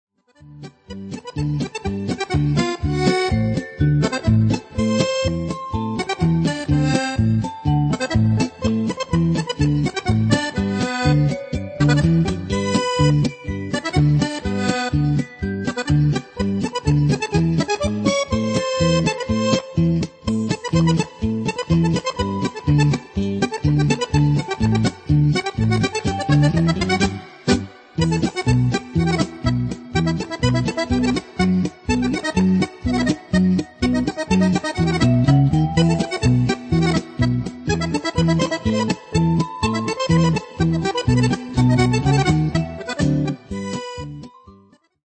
polca